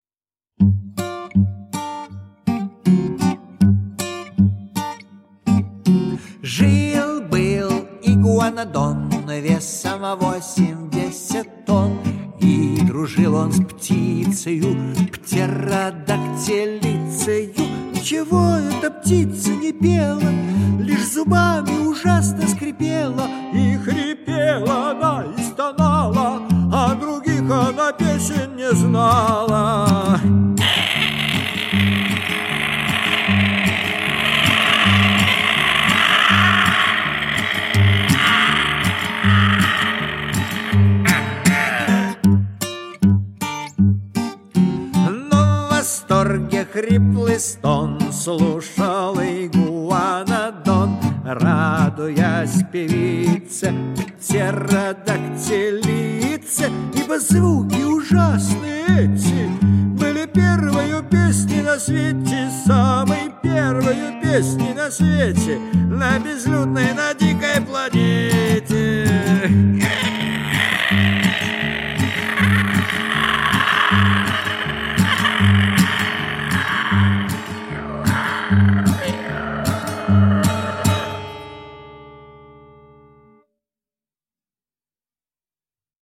Скрипящий звук на вдохе.